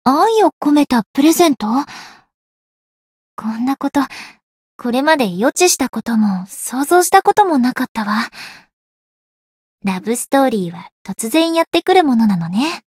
灵魂潮汐-星见亚砂-情人节（送礼语音）.ogg